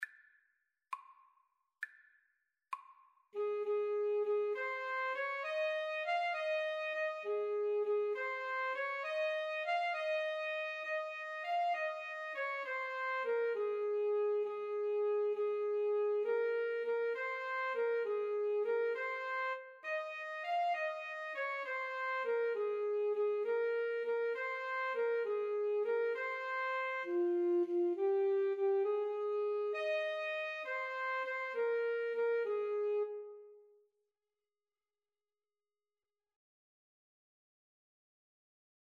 Free Sheet music for Alto Saxophone Duet
Ab major (Sounding Pitch) Eb major (French Horn in F) (View more Ab major Music for Alto Saxophone Duet )
6/8 (View more 6/8 Music)
Classical (View more Classical Alto Saxophone Duet Music)